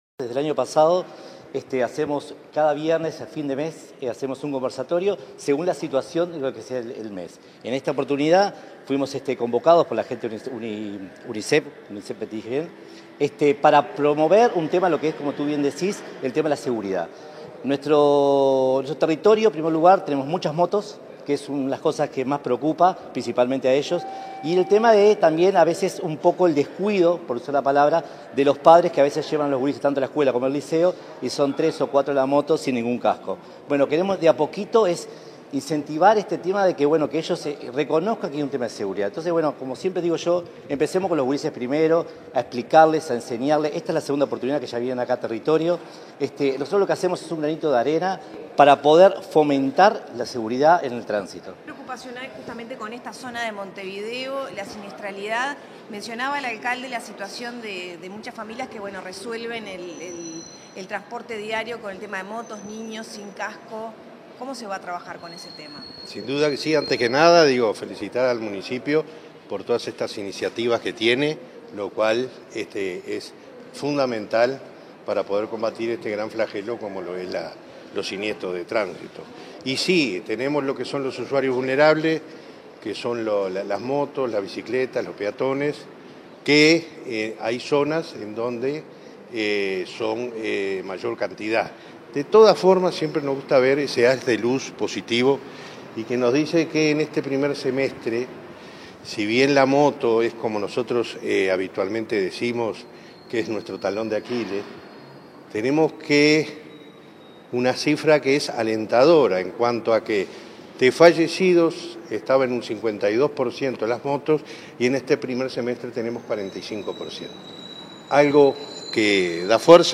Declaraciones a la prensa del presidente de Unasev, Alejandro Draper, y el alcalde del Municipio F de Montevideo, Juan Pedro López
Tras el evento, el presidente de esa dependencia y el alcalde efectuaron declaraciones a la prensa.